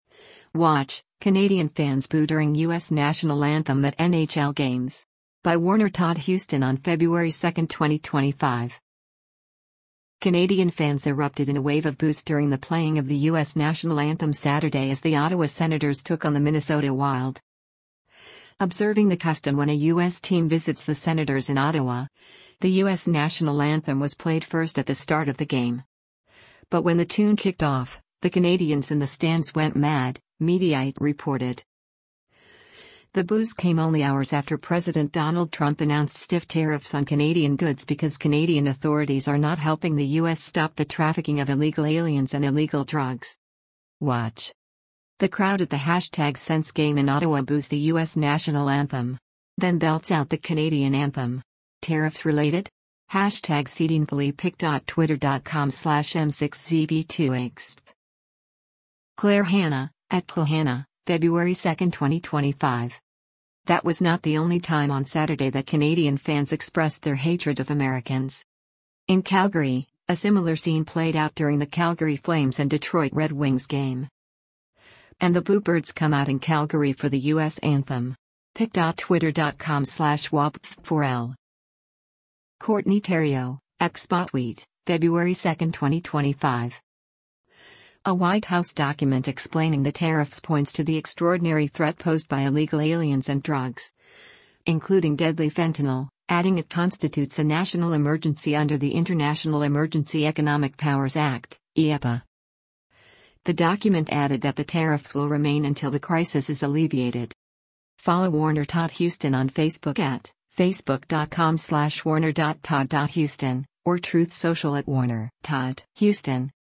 Canadian fans erupted in a wave of boos during the playing of the U.S. national anthem Saturday as the Ottawa Senators took on the Minnesota Wild.
Observing the custom when a U.S. team visits the Senators in Ottawa, the U.S. national anthem was played first at the start of the game. But when the tune kicked off, the Canadians in the stands went mad, Mediaite reported.